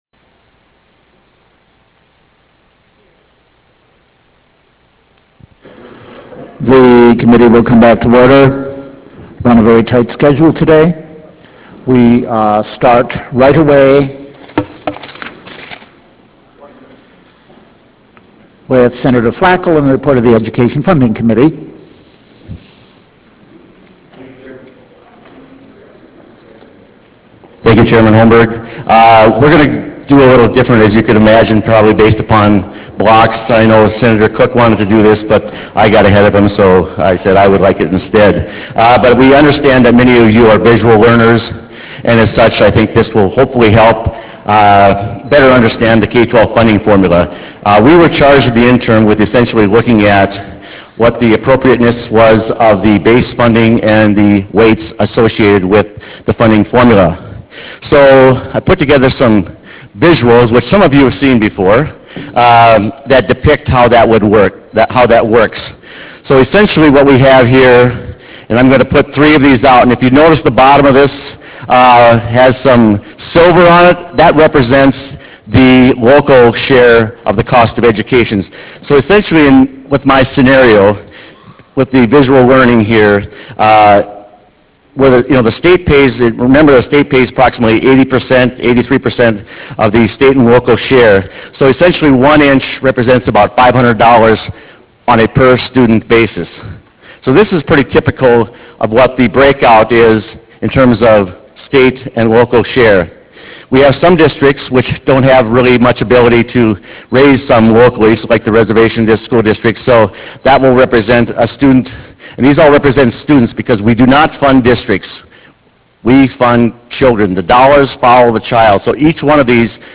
House Chamber State Capitol Bismarck, ND United States